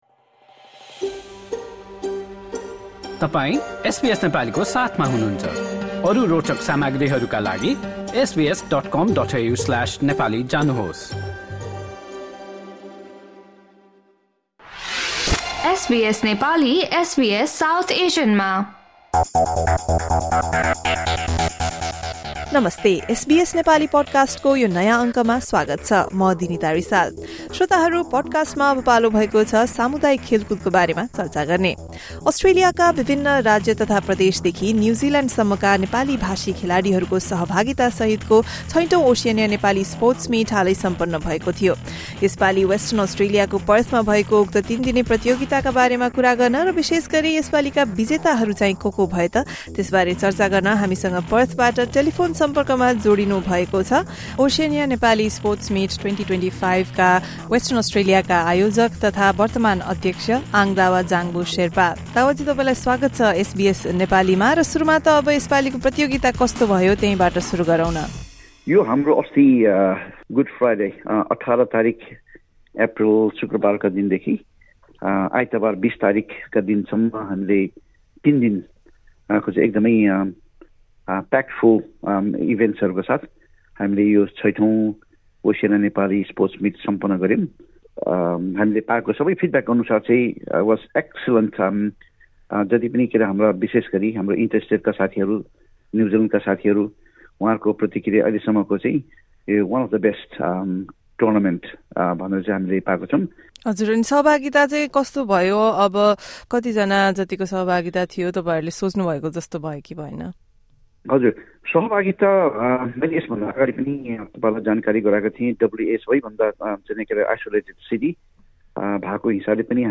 हाम्रो कुराकानी सुन्नुहोस्।